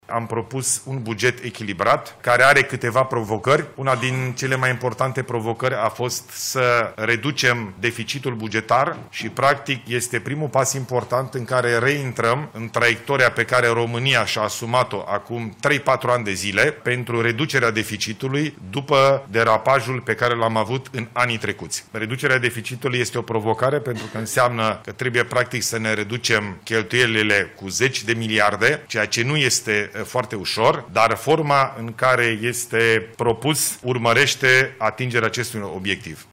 Anunțul a fost făcut de premierul Ilie Bolojan la sfârșitul unei ședințe de guvern amânate de două ori, în contextul vizitei președintelui ucrainean Volodimir Zelenski.